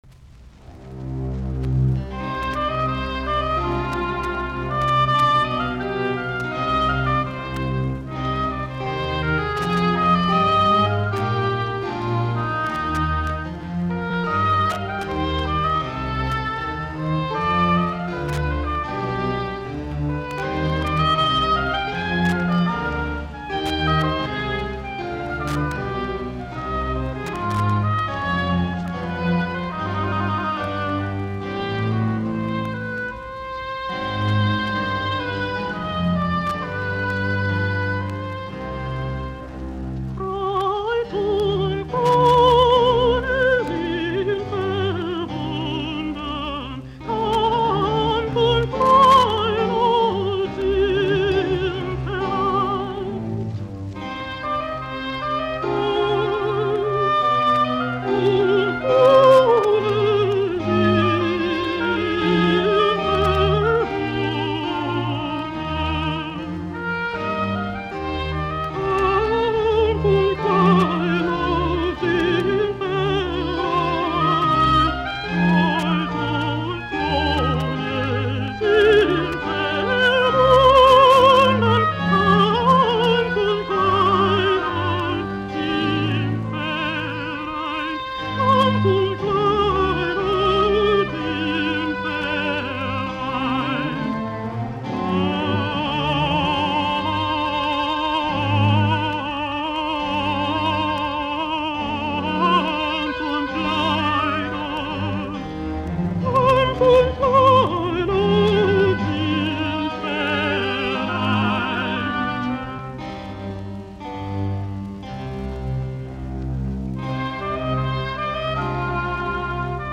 musiikkiäänite
altto
-1 LP-äänilevy.